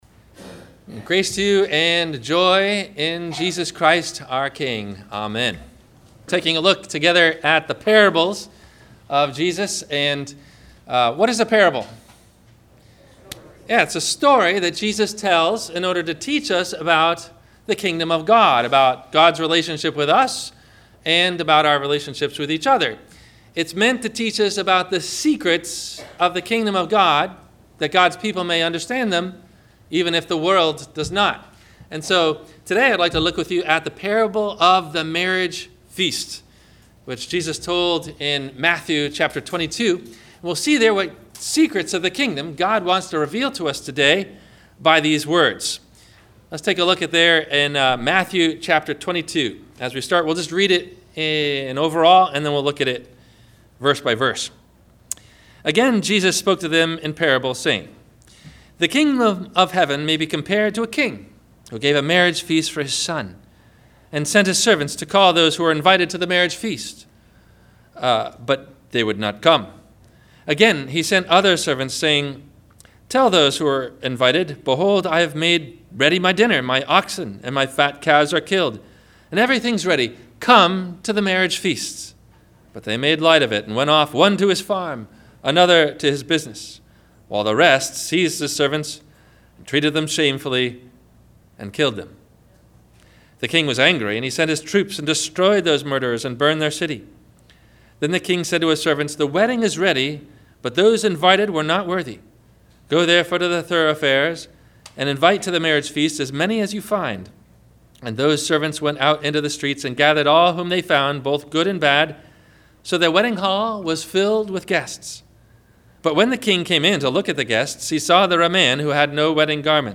The Parable of the Wedding Feast - 2nd Sunday Advent - Sermon - December 04 2016 - Christ Lutheran Cape Canaveral